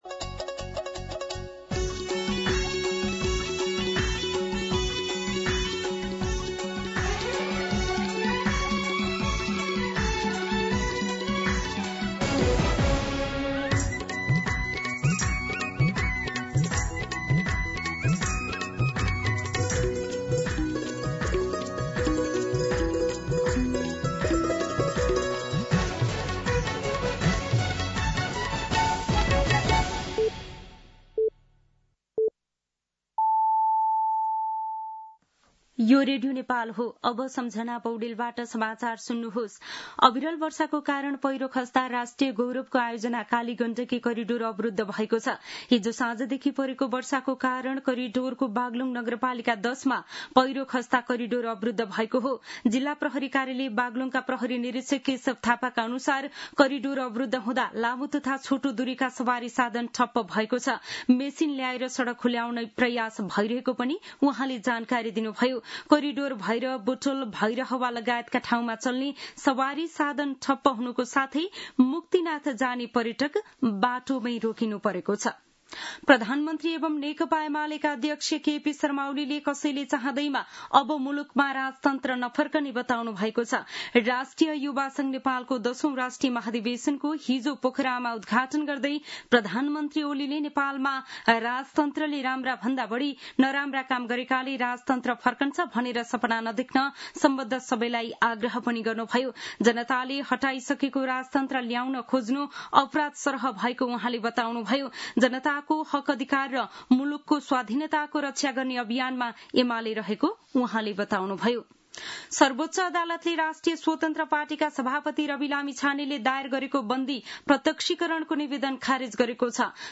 मध्यान्ह १२ बजेको नेपाली समाचार : १० जेठ , २०८२